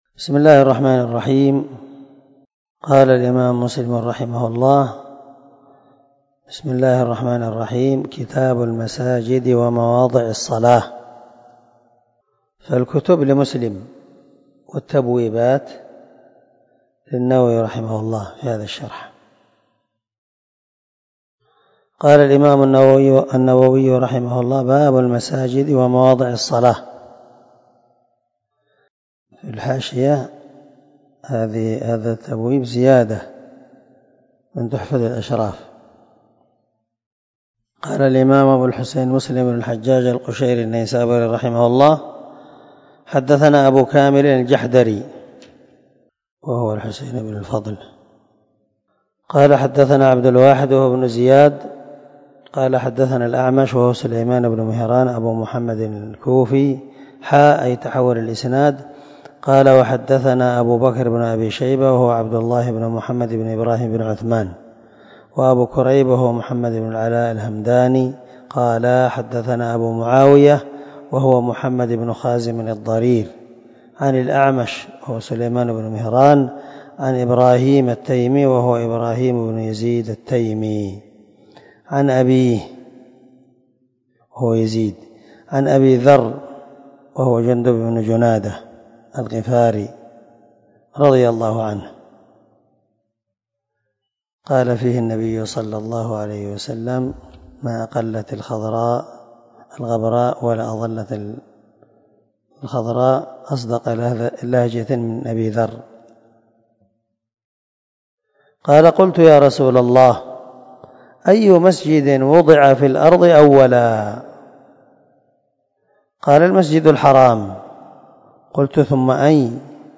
سلسلة_الدروس_العلمية
دار الحديث- المَحاوِلة-